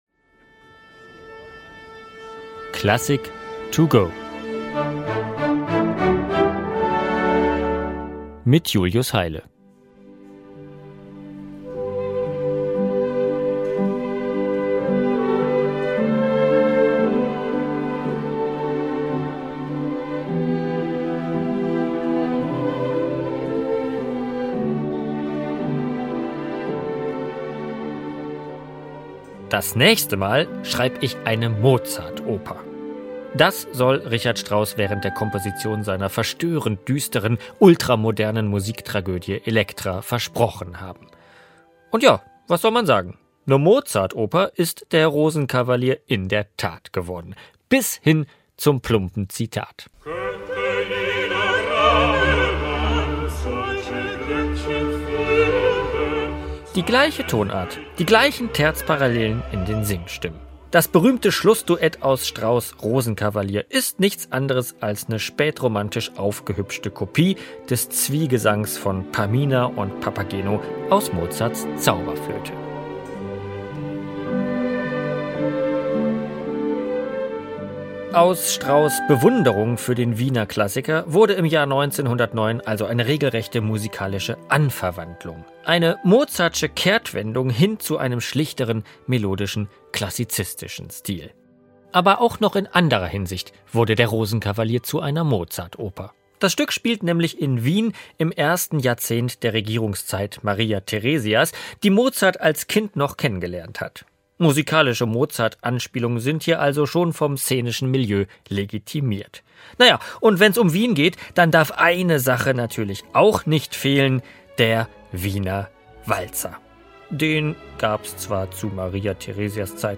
ein  Destillat aus Walzertaumel, Mozart-Zitaten, dem
nostalgischen Klang eines goldenen Wiens und Melodien, die zum